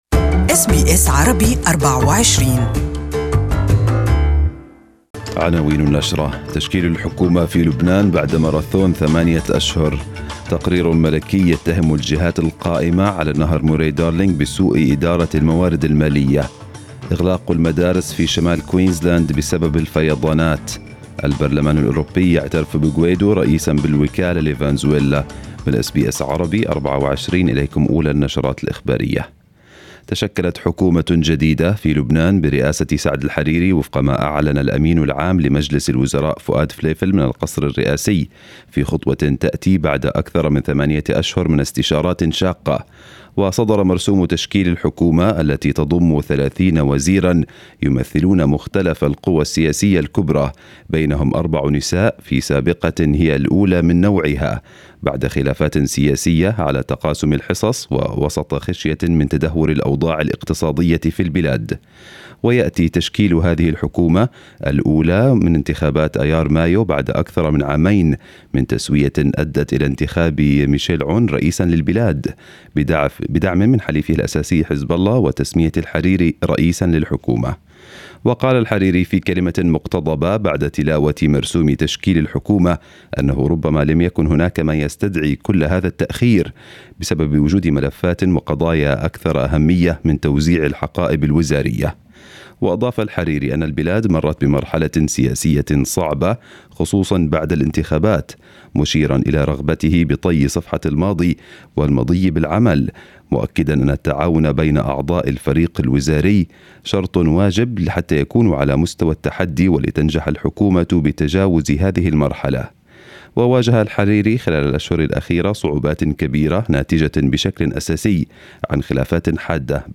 News bulletin of the day in Arabic